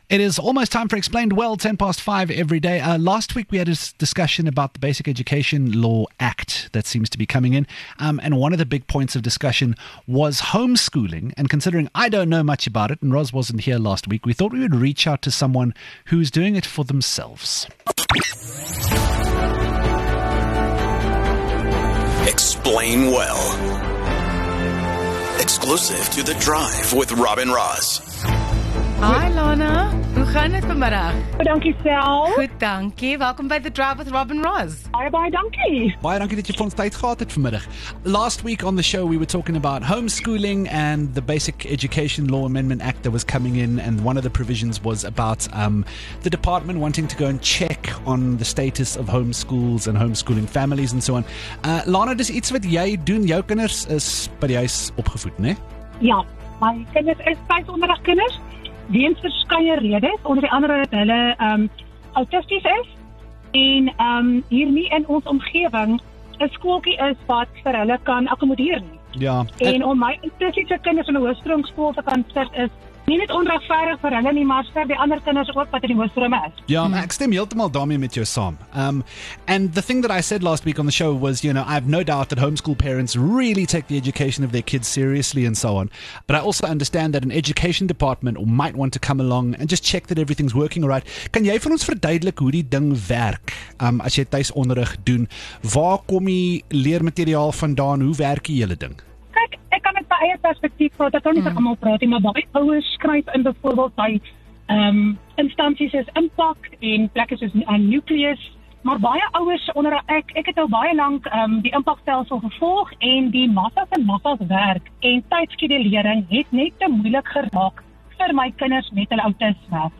The Bill on Basic Education Law is in the spotlight again and there are some amendments that could be affecting homeschoolers. We spoke to a mom who homeschools her children about why she chose this route, what it entails and how she feels about this proposed Bill.